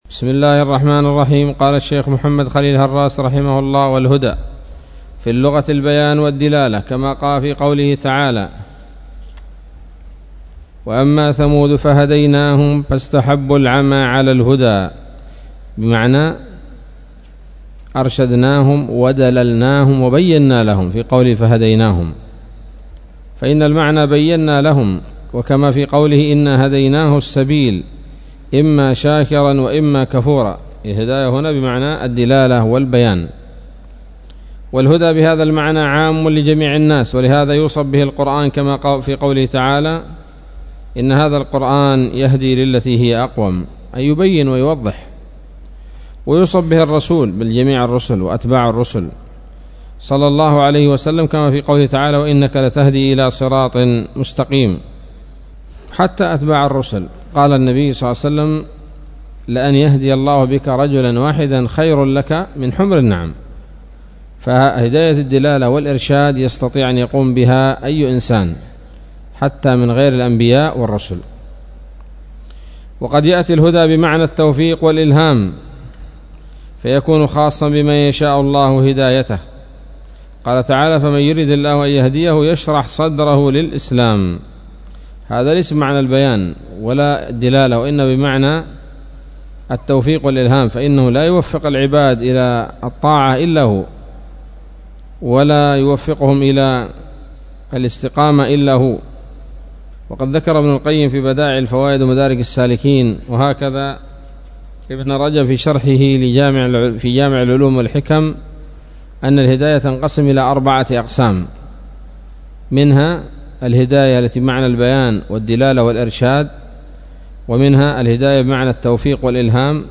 الدرس الحادي عشر من شرح العقيدة الواسطية للهراس